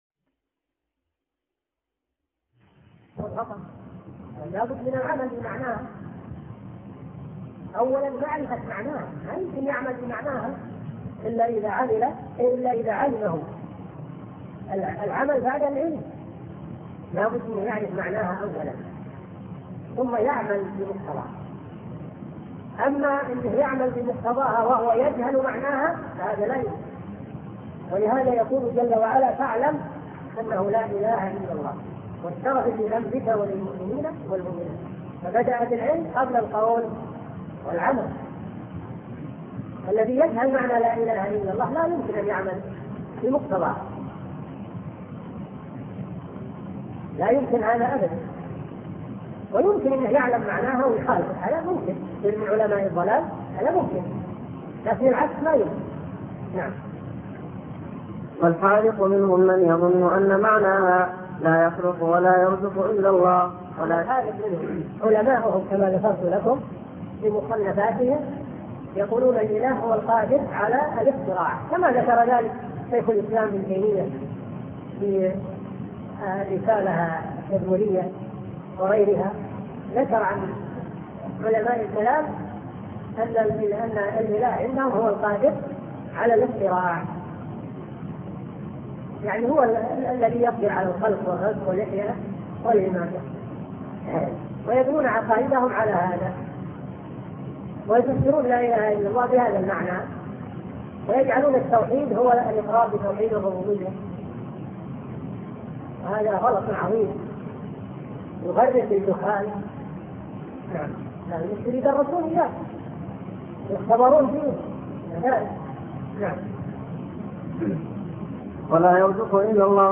الدرس الثالث